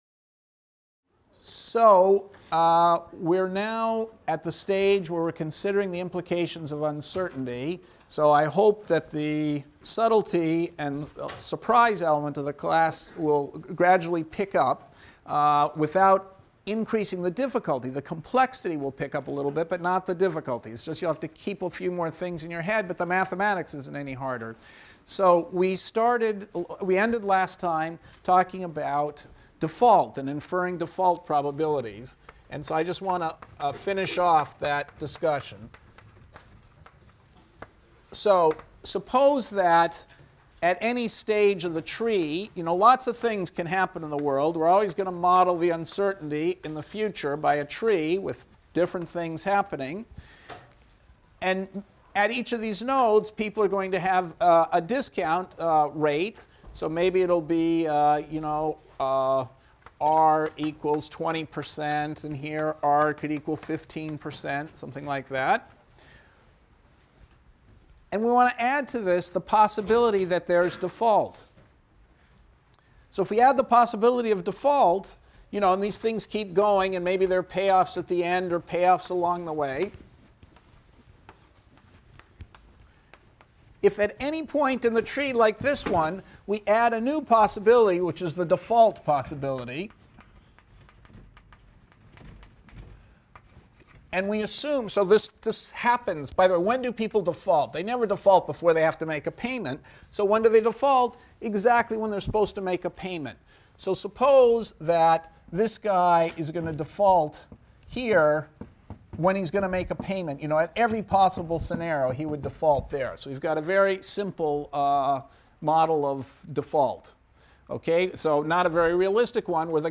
ECON 251 - Lecture 16 - Backward Induction and Optimal Stopping Times | Open Yale Courses